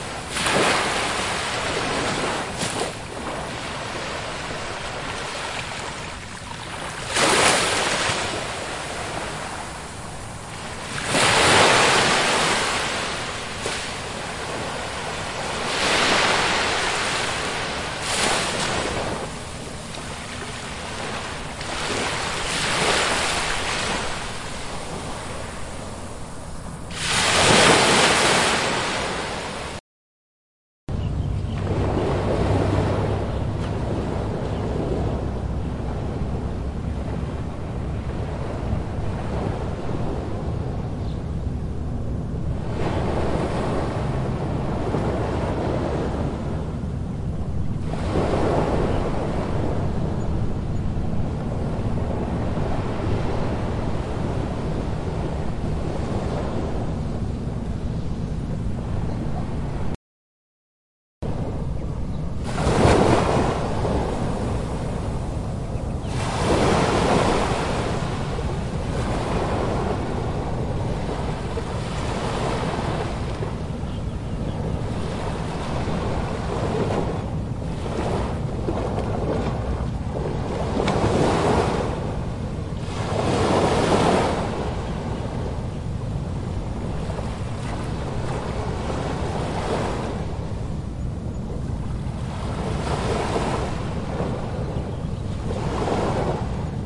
古巴 " 波浪海滩中3近
描述：波浪海滩中等关闭
标签： 海浪 沙滩
声道立体声